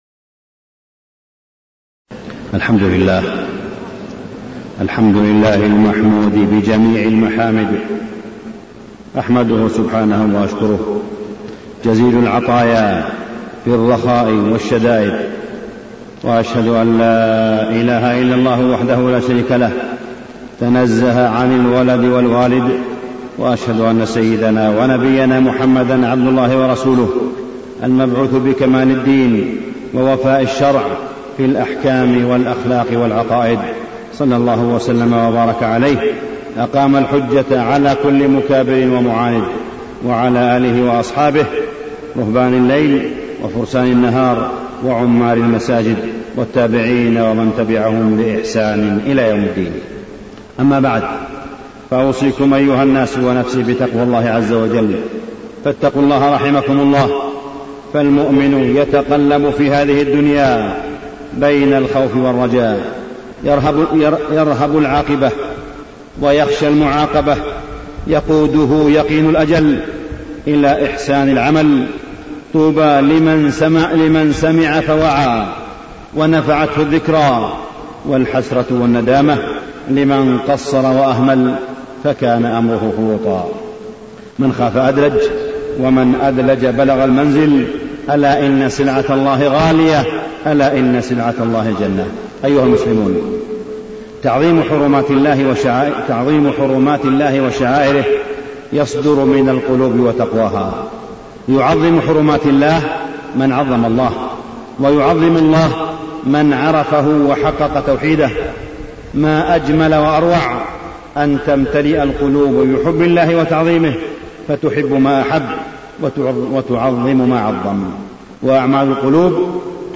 تاريخ النشر ٢٧ ذو القعدة ١٤٢٨ هـ المكان: المسجد الحرام الشيخ: معالي الشيخ أ.د. صالح بن عبدالله بن حميد معالي الشيخ أ.د. صالح بن عبدالله بن حميد تعظيم الشعائر والحرمات